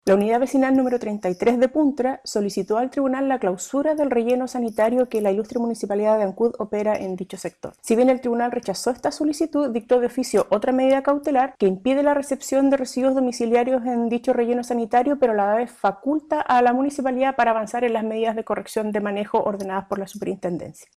Respecto a esta resolución se refirió Sibel Villalobos Volpi, Ministra Titular del Tercer Tribunal Ambiental de Valdivia: